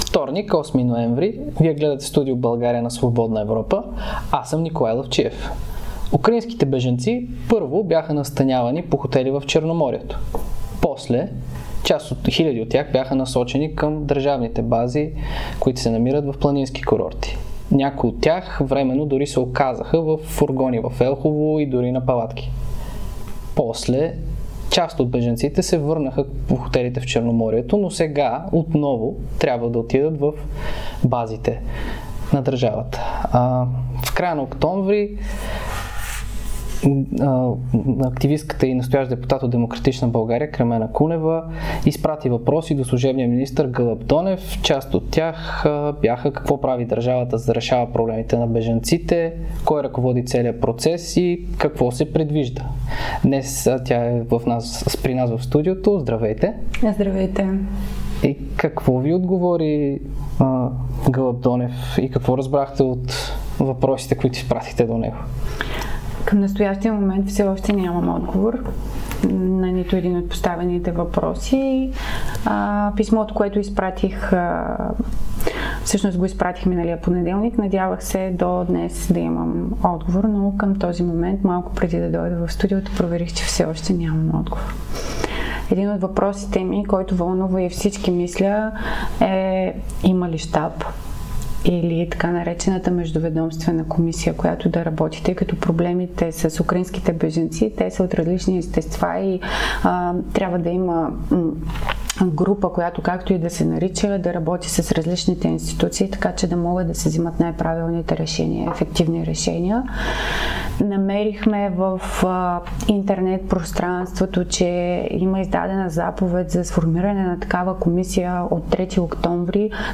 Това каза депутатът от "Демократична България" Кремена Кунева в интервю за Свободна Европа. По думите ѝ основният проблем за украинските бежанци в България е, че няма план за интеграция.